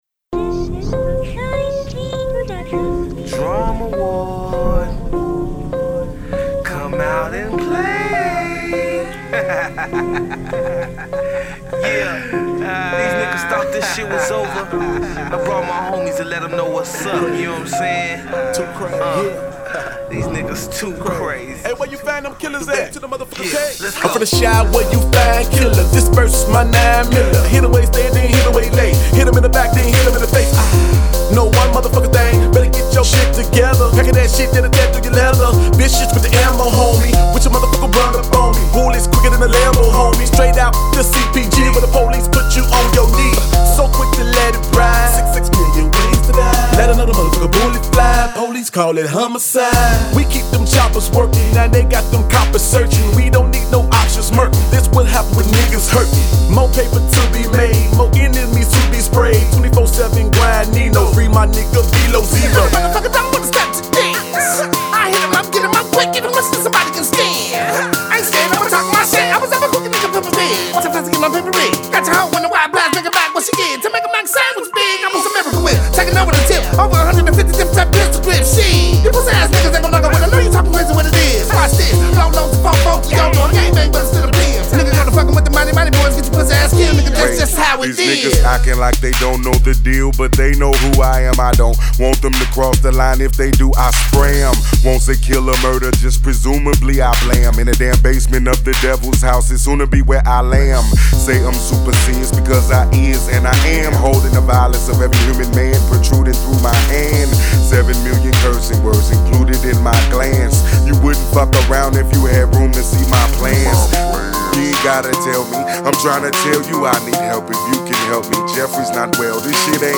” an anthem featuring the originators from out West.